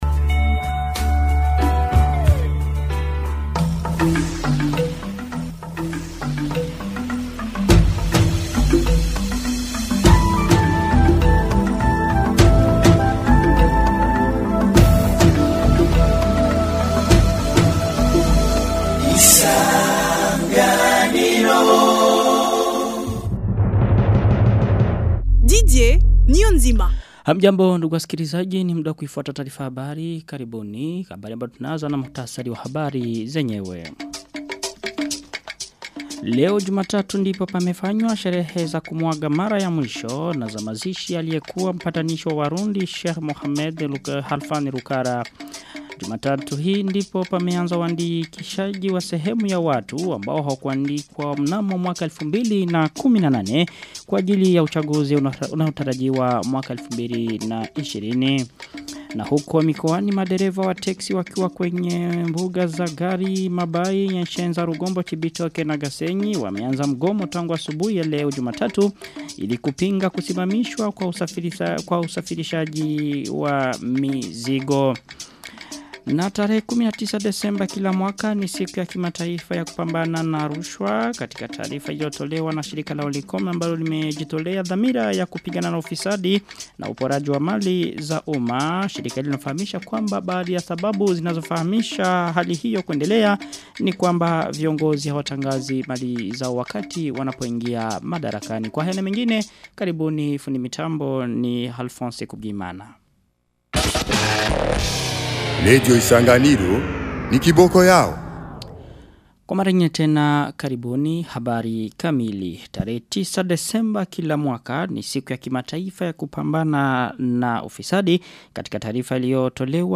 Taarifa ya habali tarehe 9 desemba 2019